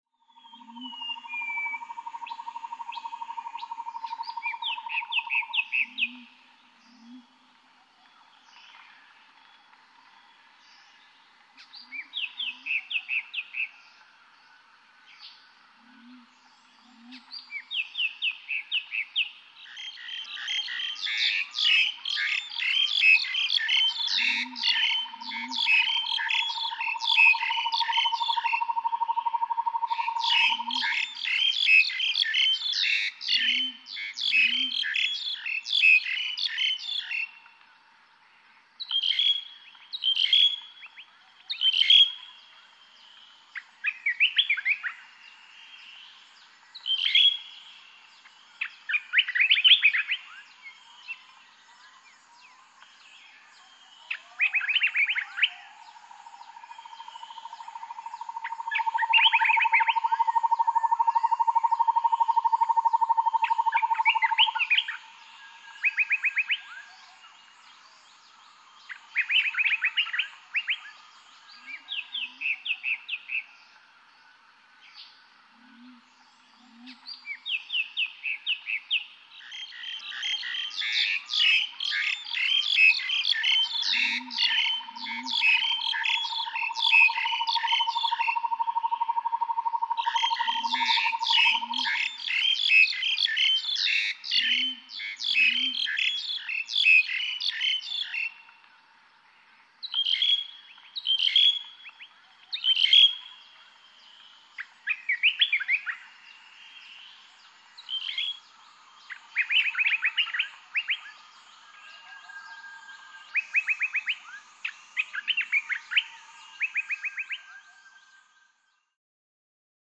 Звуки леса
Азиатский лес, звуки дня